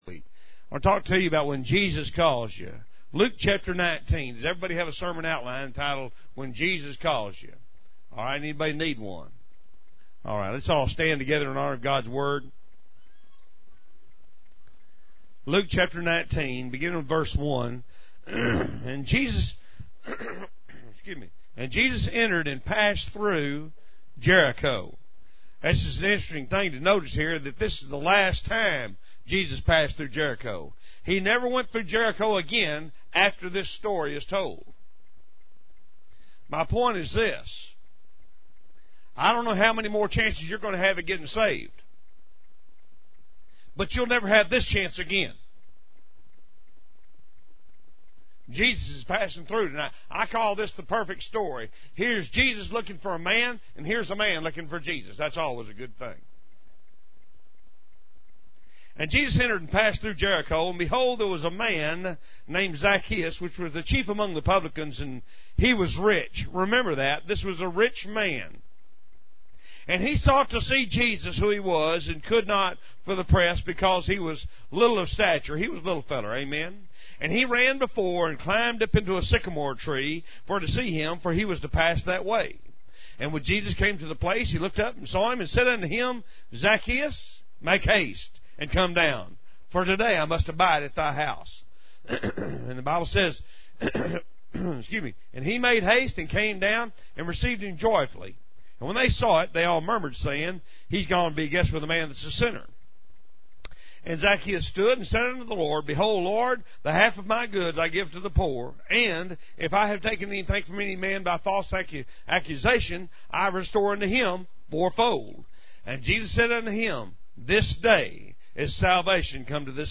In this sermon, the preacher emphasizes the personal call of Jesus to individuals. He uses the story of Zacchaeus, a tax collector, as an example of someone who was called by Jesus and responded joyfully.